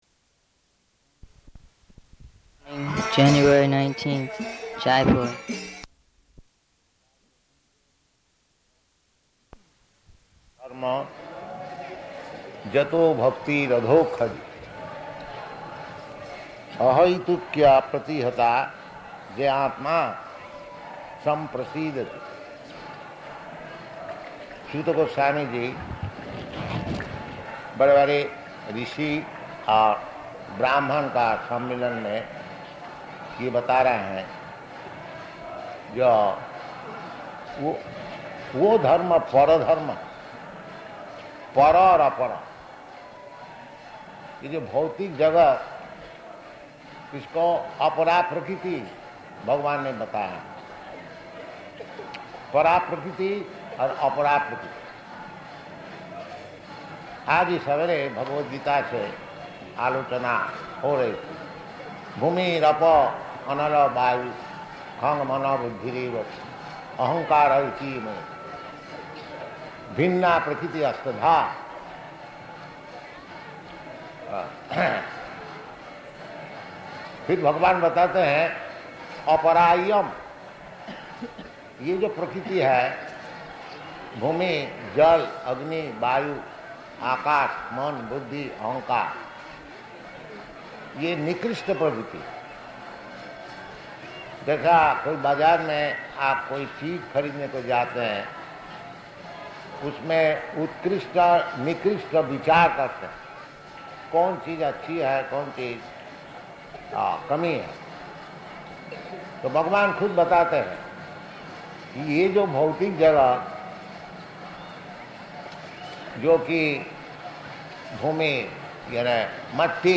Lecture in Hindi
Lecture in Hindi --:-- --:-- Type: Lectures and Addresses Dated: January 19th 1972 Location: Jaipur Audio file: 720119LE.JAI.mp3 Devotee: January 19th, Jaipur.